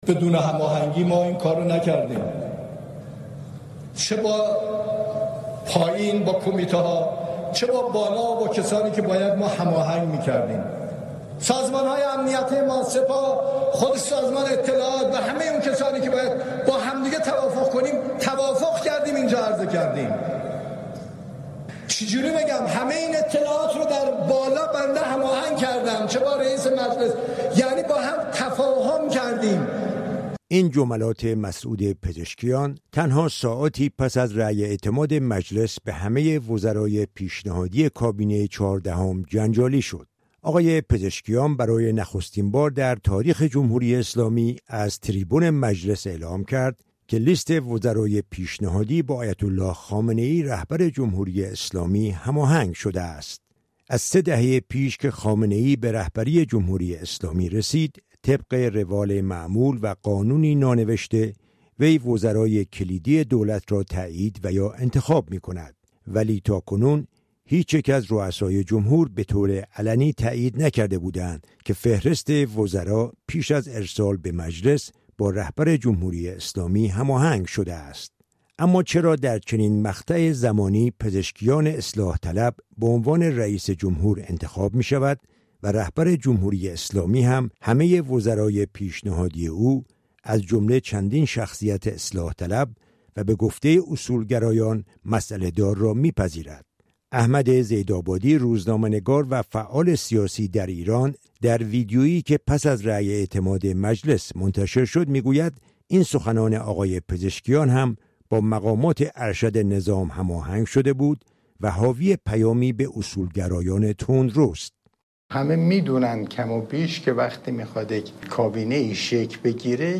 گزارش رادیویی از واکنش‌ها به اظهارات پزشکیان در مجلس درباره کابینه